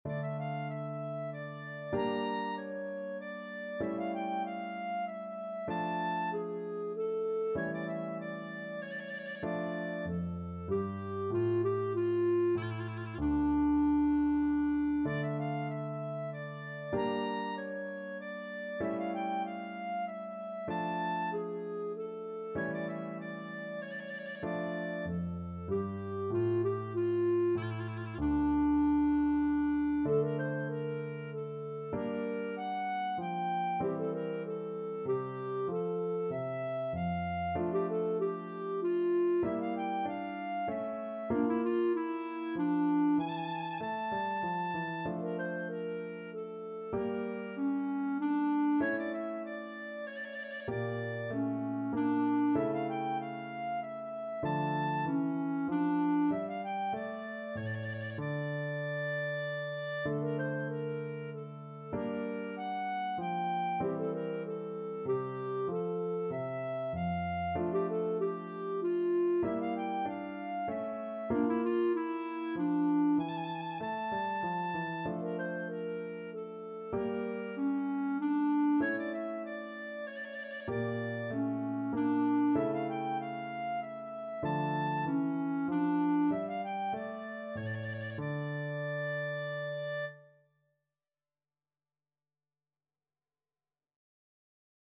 Clarinet
3/8 (View more 3/8 Music)
D minor (Sounding Pitch) E minor (Clarinet in Bb) (View more D minor Music for Clarinet )
Classical (View more Classical Clarinet Music)